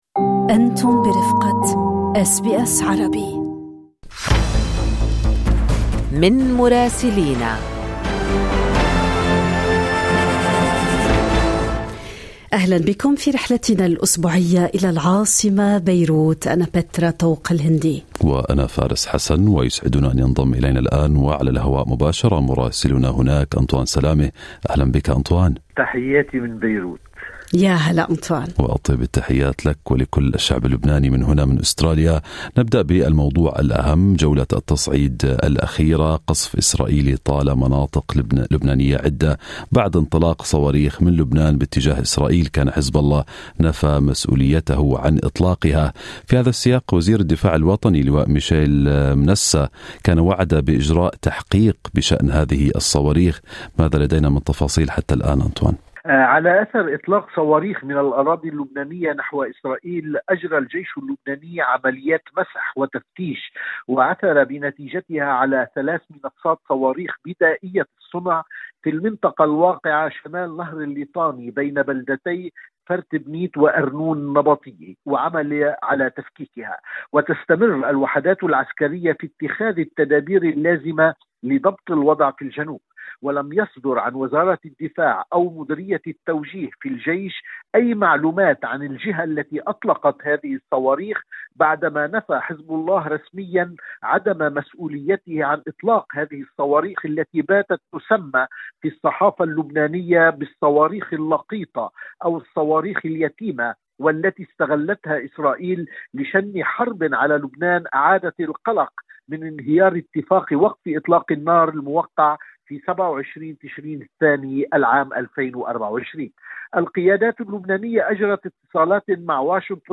من مراسلينا: أخبار لبنان في أسبوع 25/3/2025